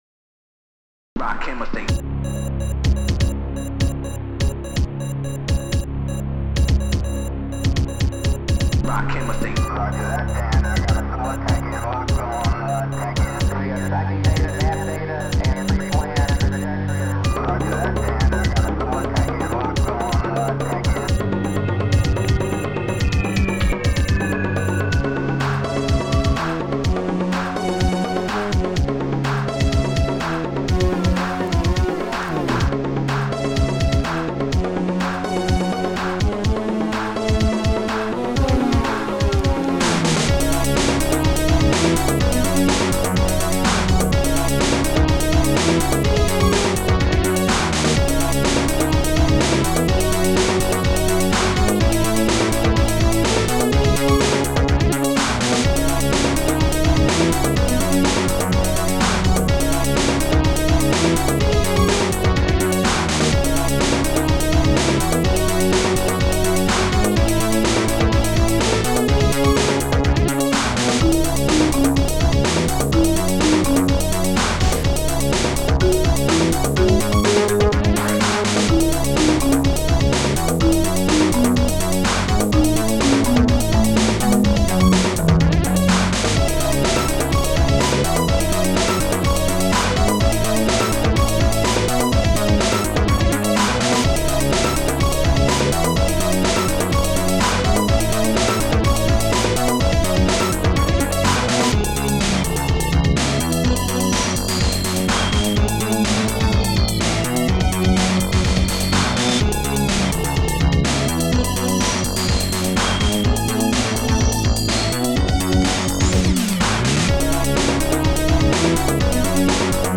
Protracker Module  |  1990-03-17  |  233KB  |  2 channels  |  44,100 sample rate  |  5 minutes, 9 seconds
st-04:rapbdrum
st-01:funbass
st-04:dmcsnare
st-01:marimba